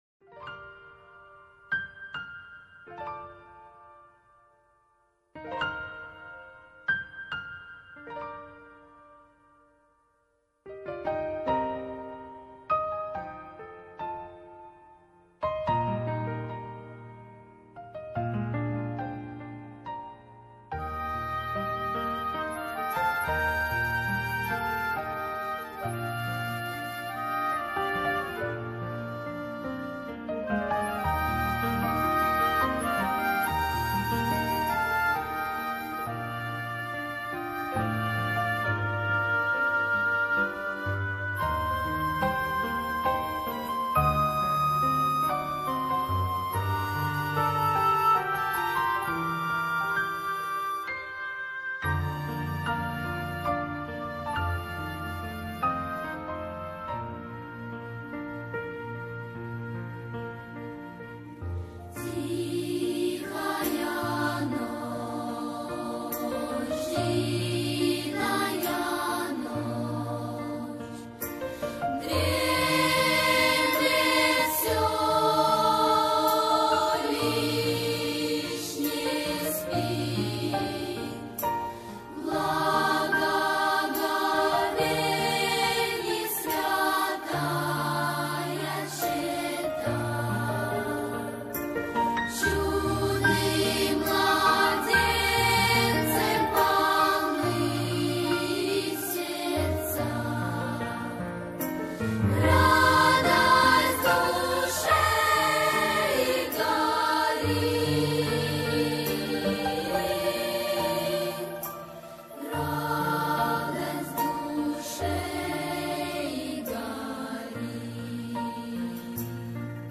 feat. Детский хор